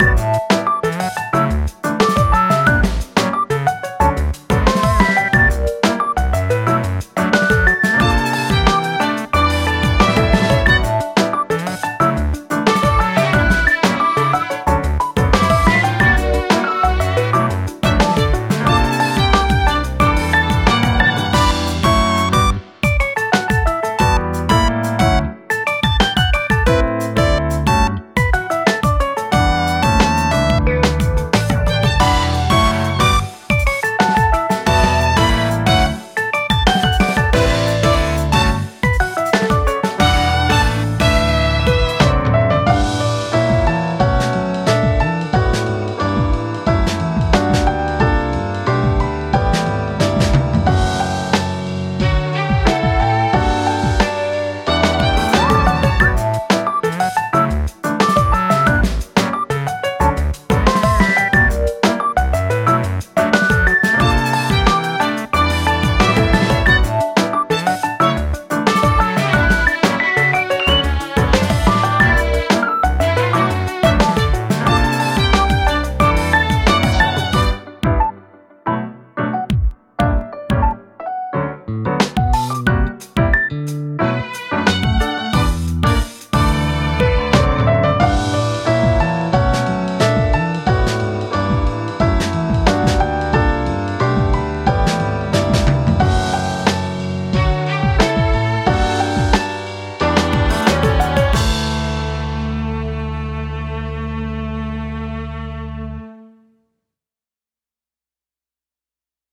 ループ用音源（BPM=90）